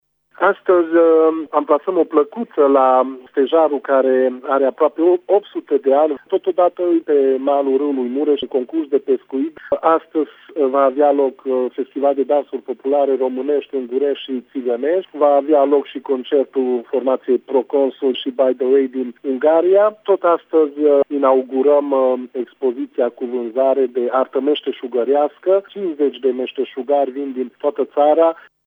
Primarul comunei Sângeorgiu de Mureș, Sofalvi Szabolcs: